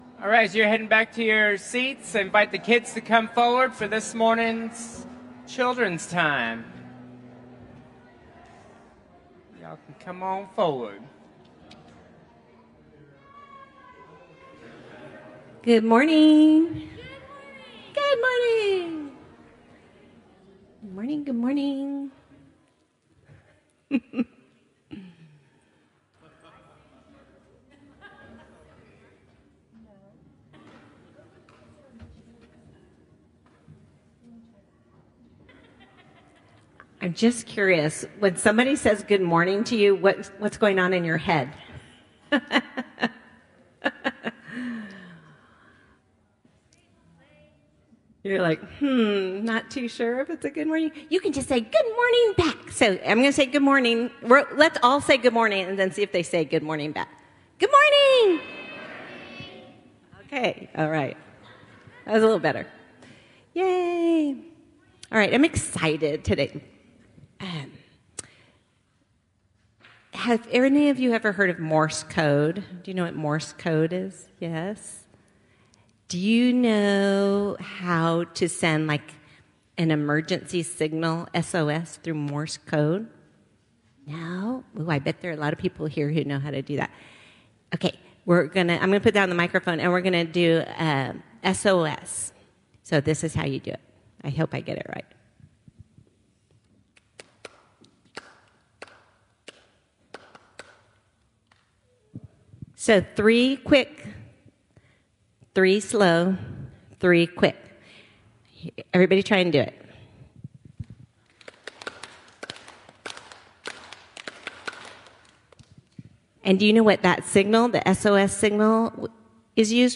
Children’s Time
Sermon Series